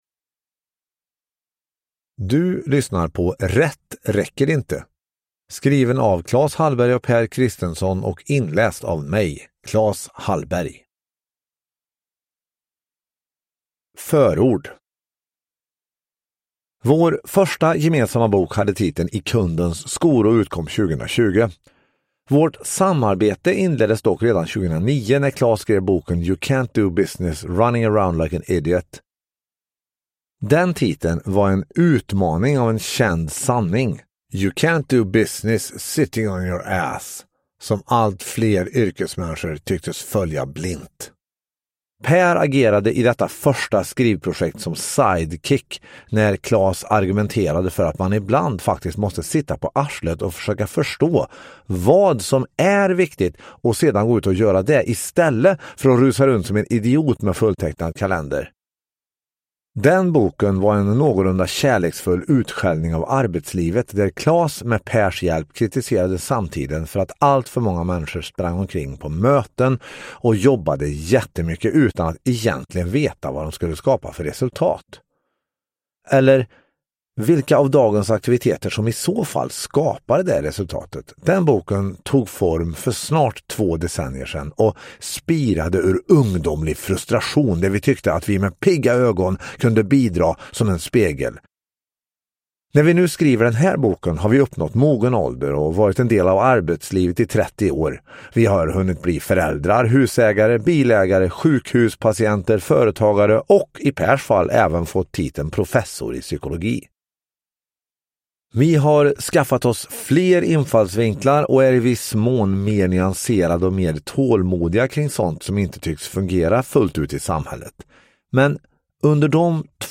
Rätt räcker inte : Att göra nytta på riktigt – Ljudbok